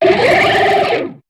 Cri de Boguérisse dans Pokémon HOME.